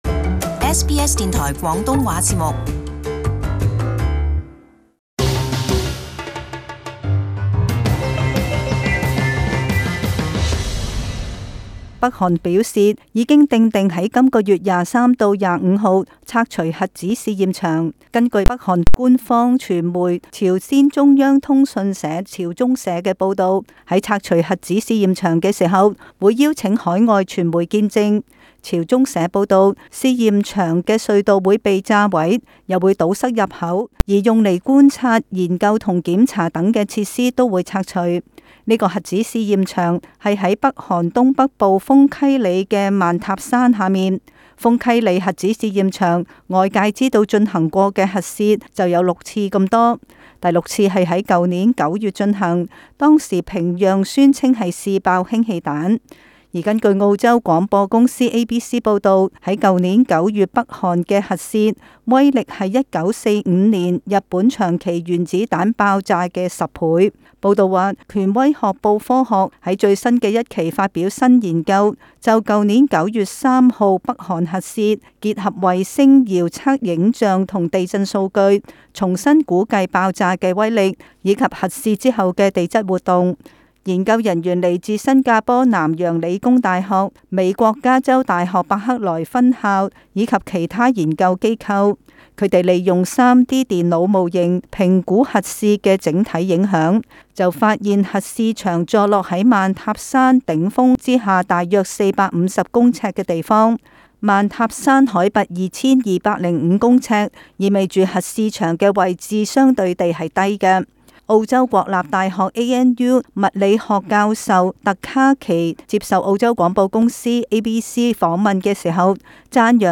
【時事報導】北韓拆除核試驗場